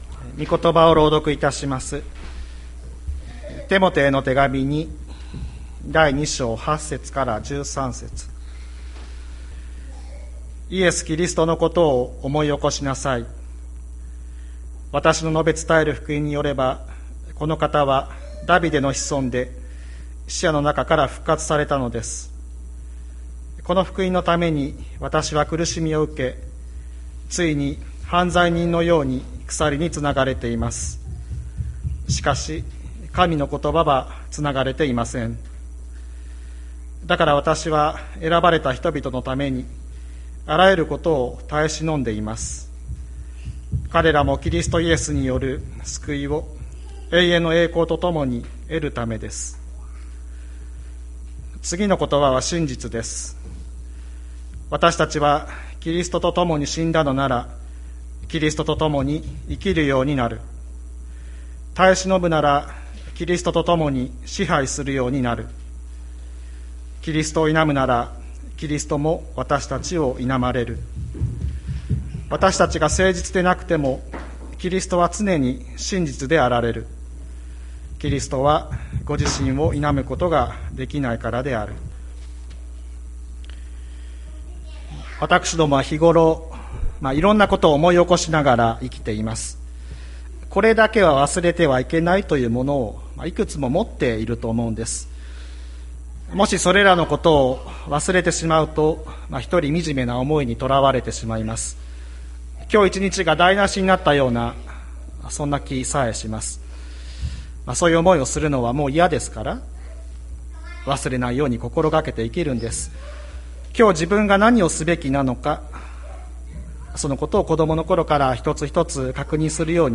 2022年07月03日朝の礼拝「忘れてはいけないもの」吹田市千里山のキリスト教会
千里山教会 2022年07月03日の礼拝メッセージ。